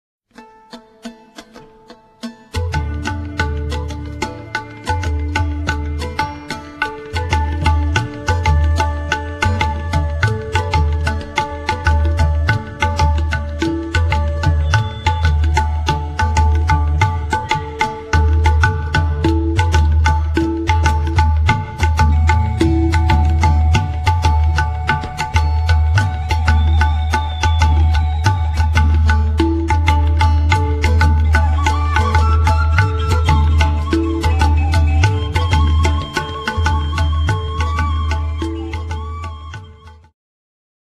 wiolonczela, sarangi, ¶piew alikwotowy
`ud, fujara słowacka, lira korbowa, drumla, duduk, głosy
tabla, bendir
baglama, saz, tanbur, ¶piew gardłowy
lira korbowa, ney, kaval
suka biłgorajska, tanpura, głosy